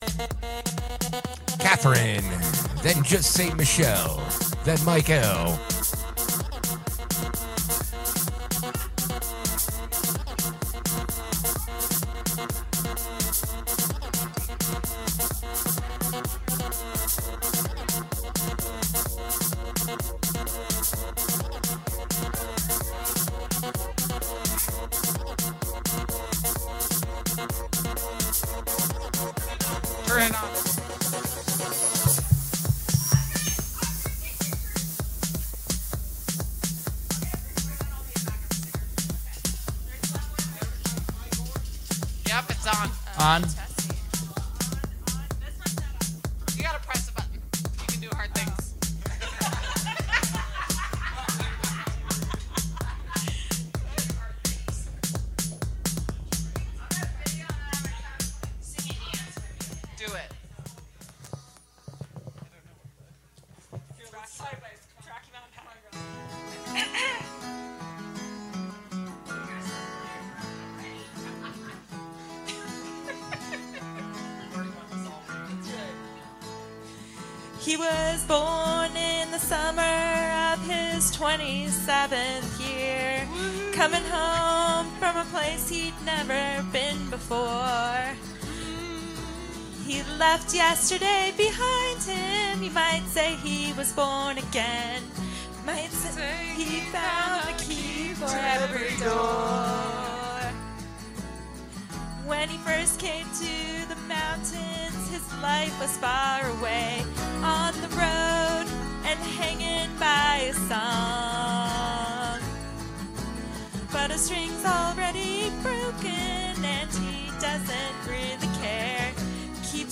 We are live 8-12 every Sunday and Wednesday from the Morrison Holiday Bar